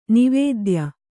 ♪ nivēdya